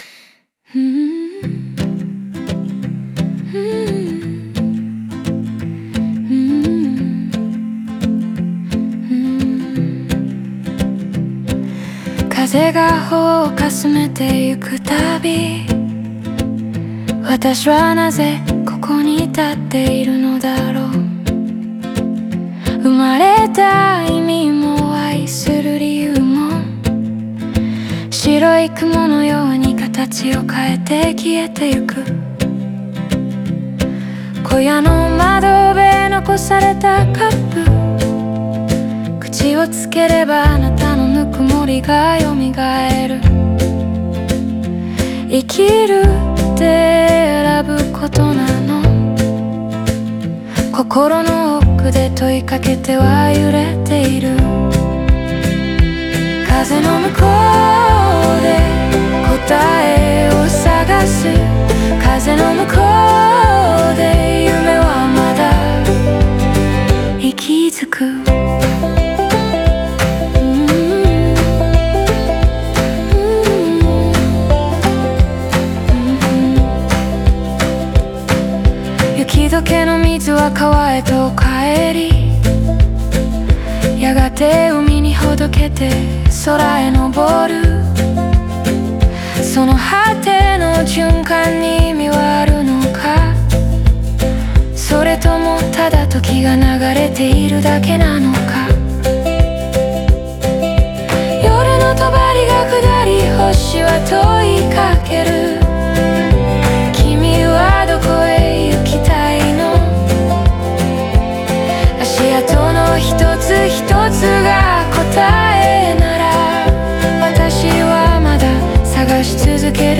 オリジナル曲♪
全体はモノローグ風で繊細に仕上げられ、淡い情緒が静かに余韻を残す構成となっています。